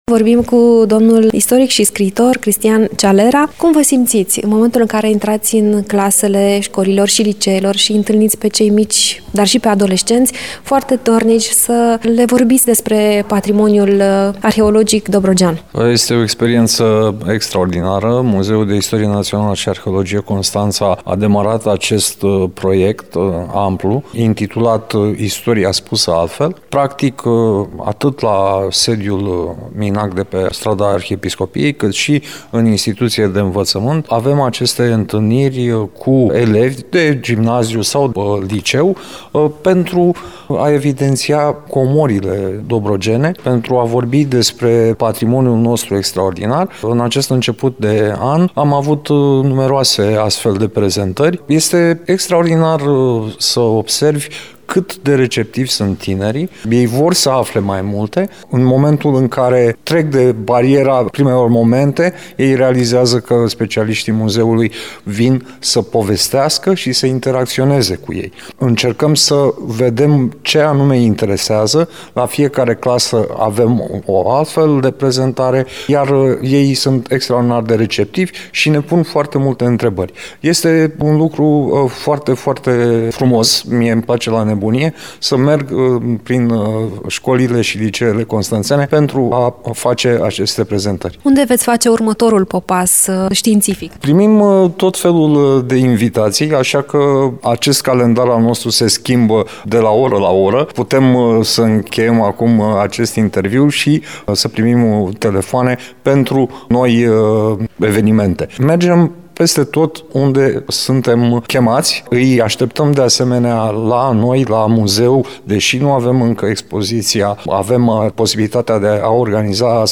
Mai multe aflăm din interviul